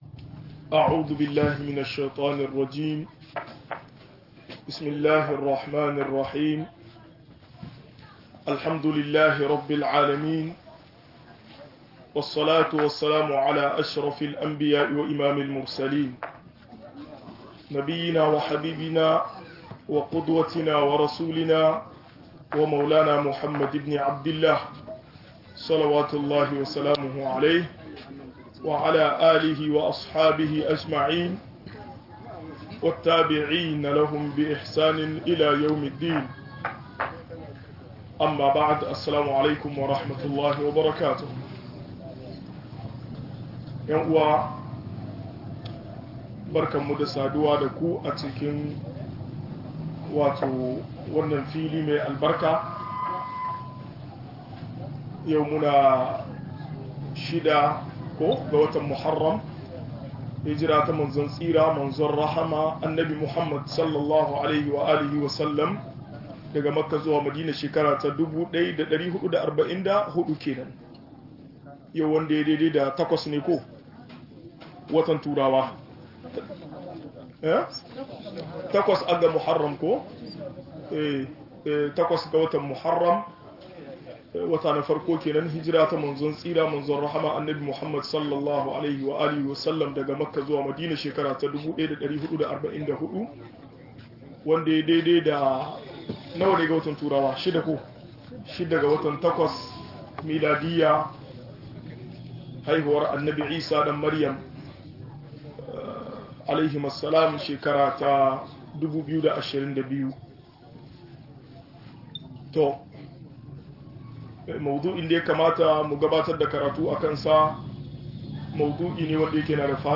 Muhimmancin ilimi ga al'umma - MUHADARA